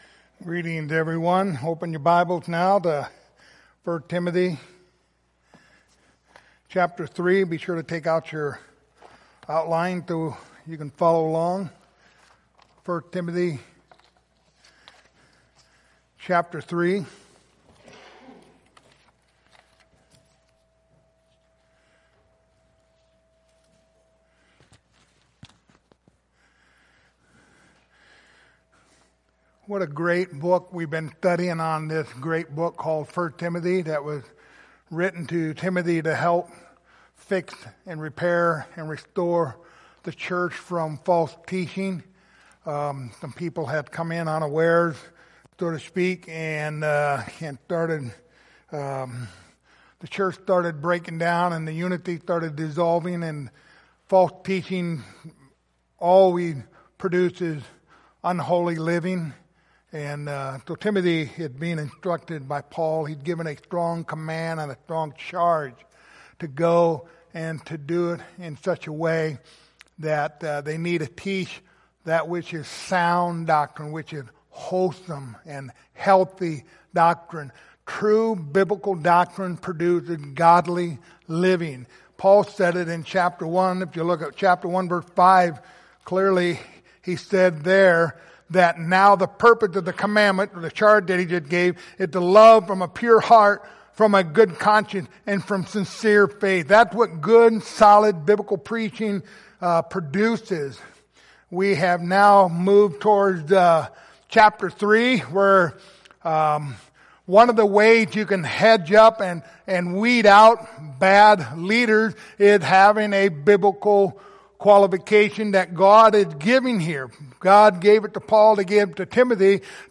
Passage: 1 Timothy 3:8-13 Service Type: Sunday Morning Topics